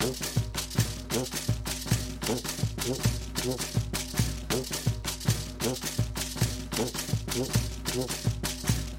Bambuco loop.mp3
Folclor Colombiano, Región Andina, instrumentos musicales, instrumentos de percusión, ritmos musicales